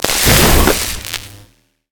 stun crystal.ogg